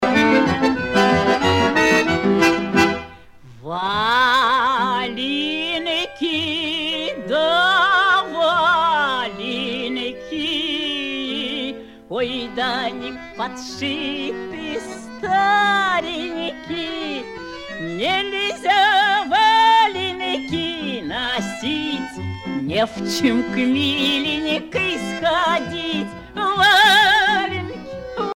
Support original : disque microsillon 33t 12 pouces
Pièce musicale éditée ( chanson, musique, discographie,... )